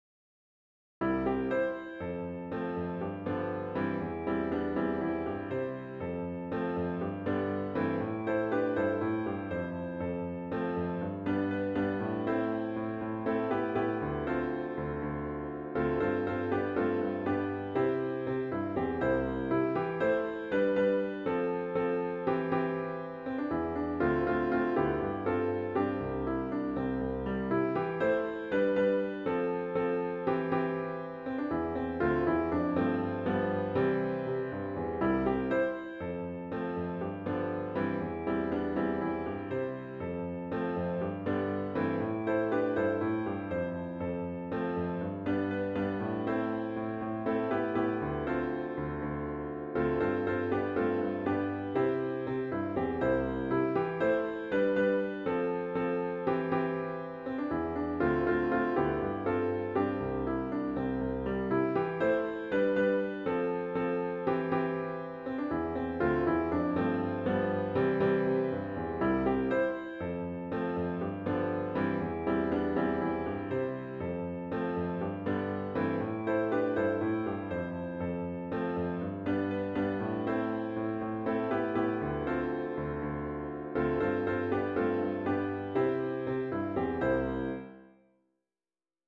OPENING HYMN   “Celebrate Love”   SF#2073